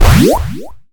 PowUp_01.mp3